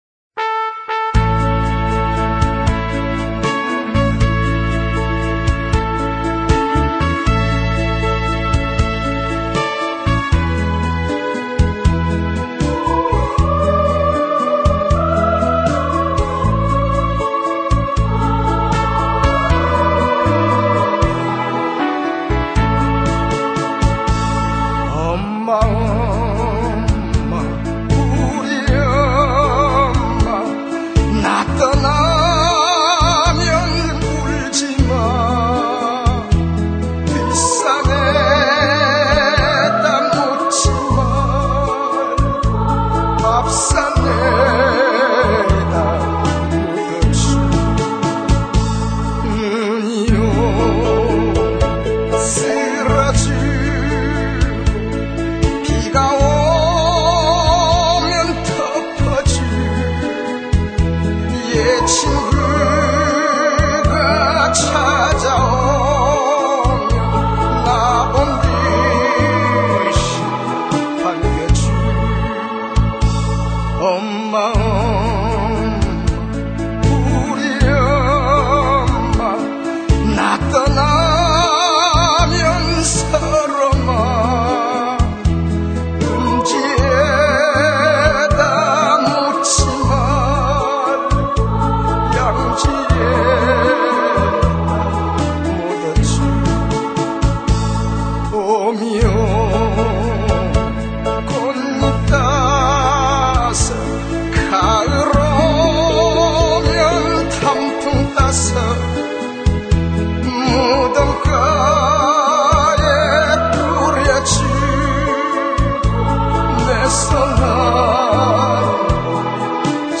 영화 '밀양'에서 아들을 잃고 절규하는 전도연 슬픈 음악 첨부 했습니다.